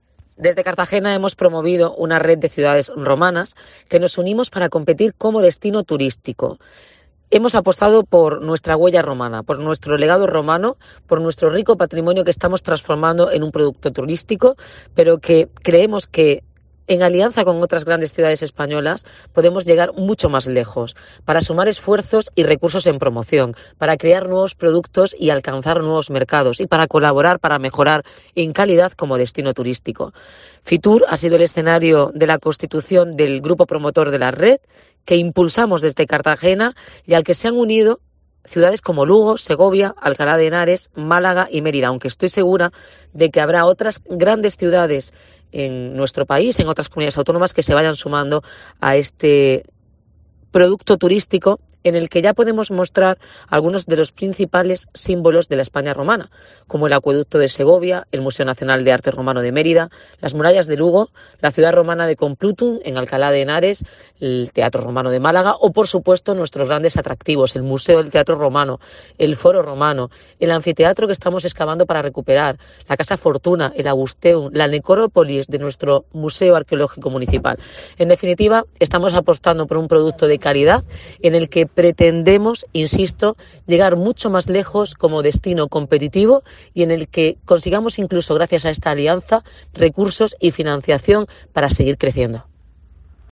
FITUR 2022
Noelia Arroyo, alcaldesa de Cartagena